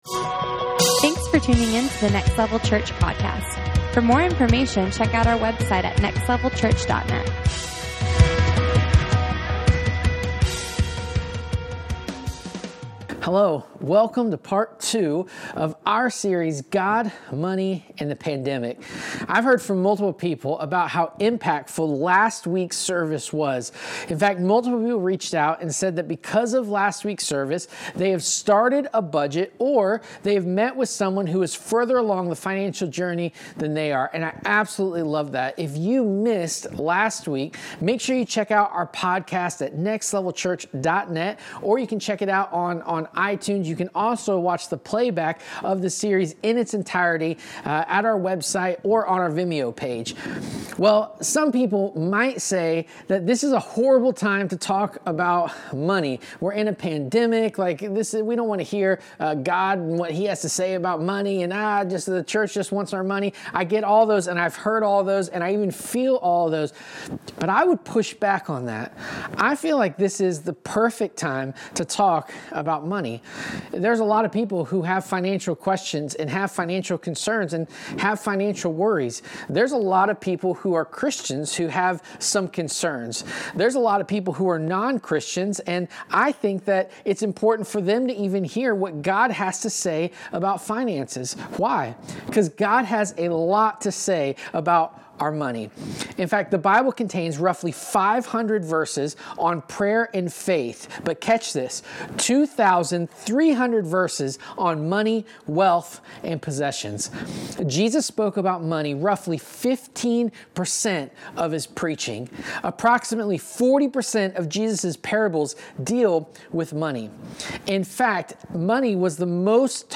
and the Pandemic Service Type: Sunday Morning « God